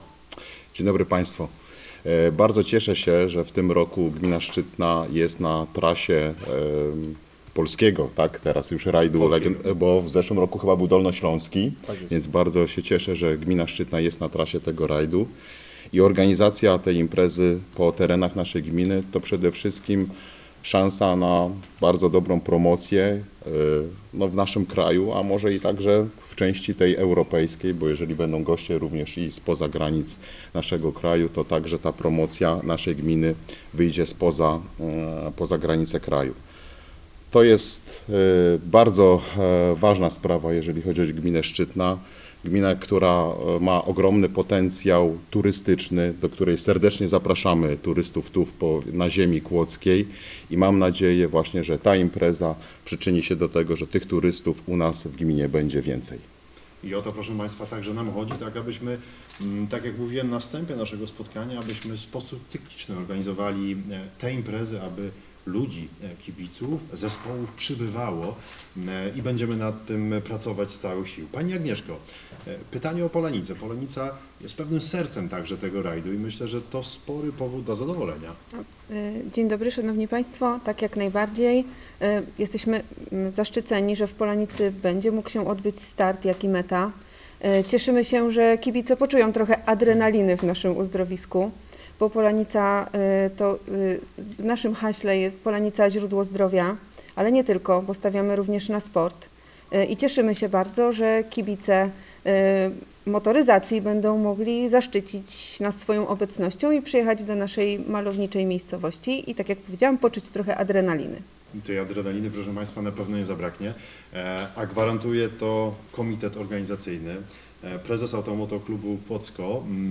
16 czerwca br. w hotelu Polanica Resort SPA odbyła się konferencja prasowa dotycząca 2. Polskiego Rajdu Legend, który rozgrywany będzie 29 i 30 sierpnia na terenie sześciu gmin powiatu kłodzkiego.